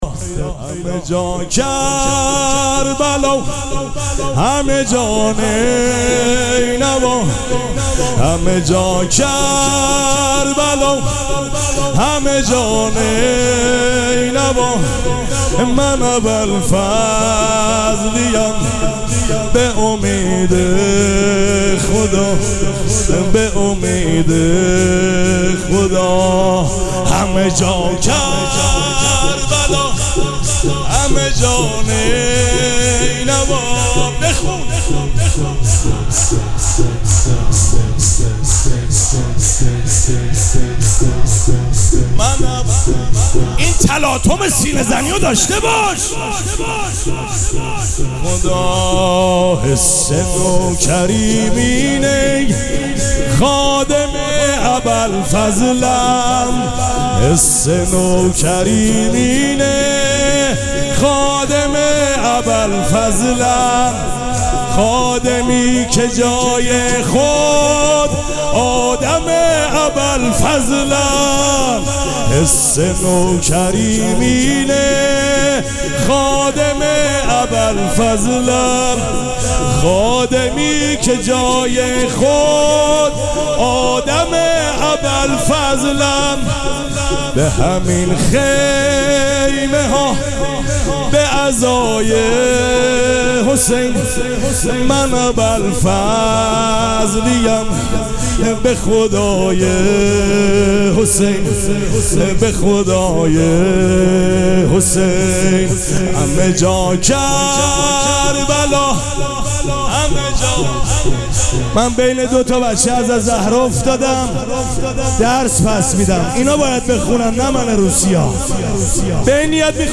شب چهارم مراسم عزاداری اربعین حسینی ۱۴۴۷
شور
مداح